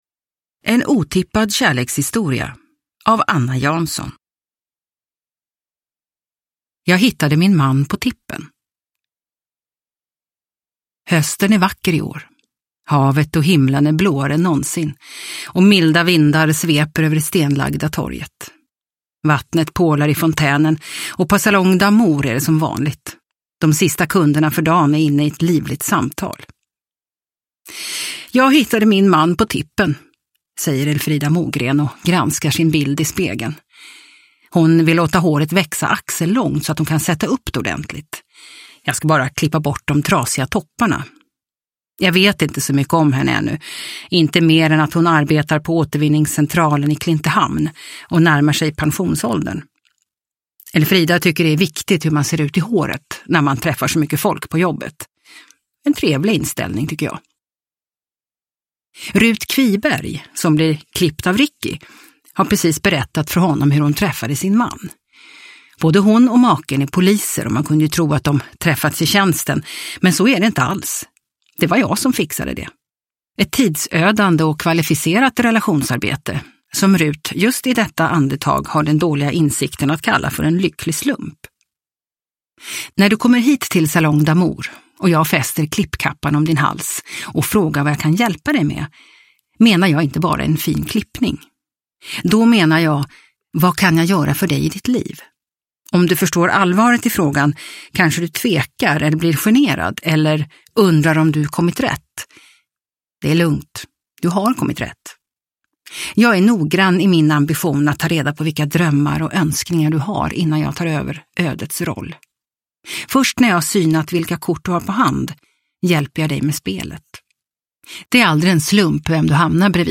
En otippad kärlekshistoria – Ljudbok – Laddas ner